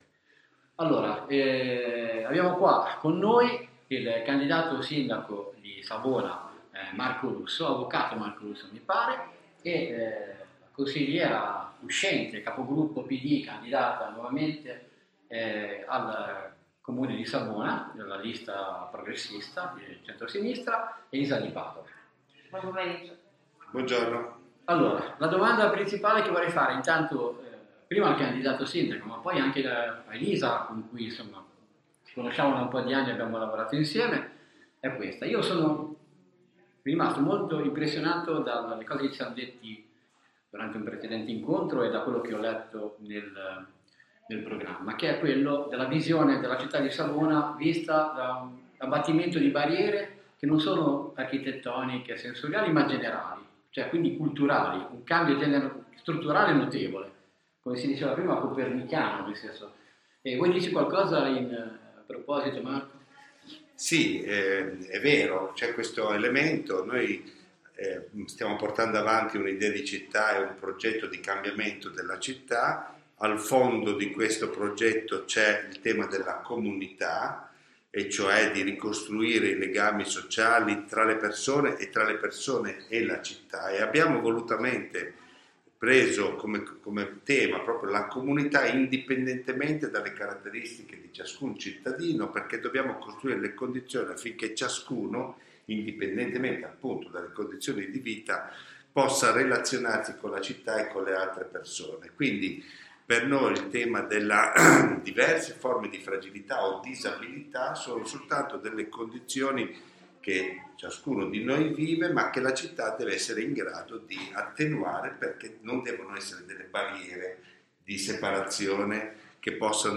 Intervista al candidato Sindaco Marco Russo e Alla Consigliera Elisa Di Padova nelle elezioni amministrative savonesi del 03-04 ottobre 2021 per il Partito Democratico!